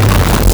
Player_Glitch [71].wav